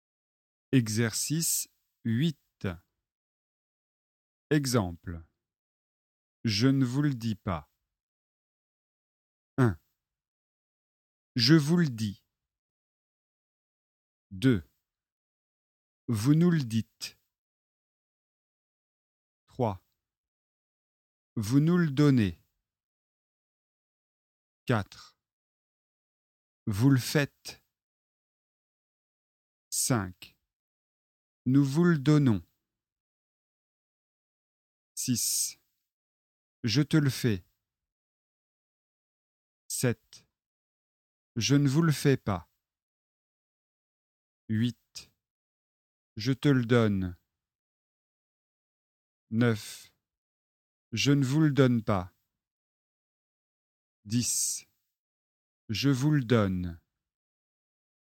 • Leçon de phonétique et exercices de prononciation
🔷 Exercice 8 : Le e muet :
Écoutez et barrez les e non prononcés.